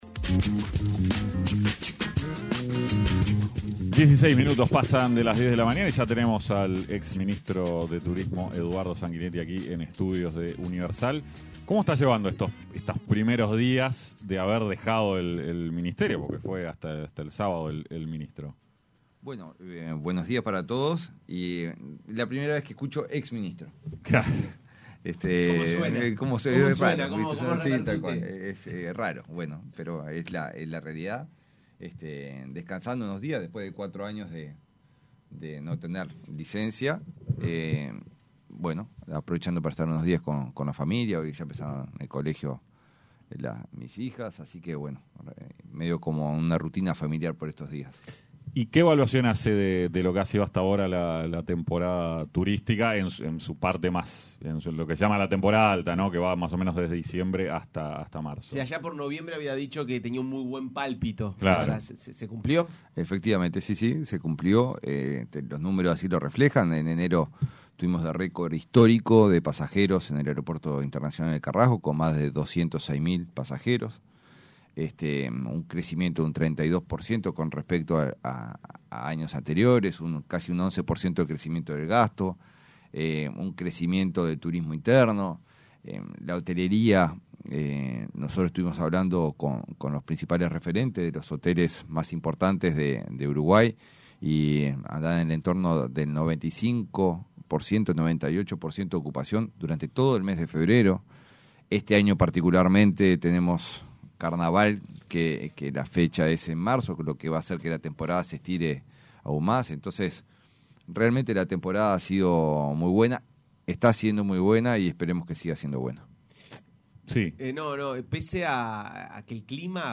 Entrevista a Eduardo Sanguinetti
ENTREVISTA-EDUARDO-SANGUINETTI-5-DEL-3.mp3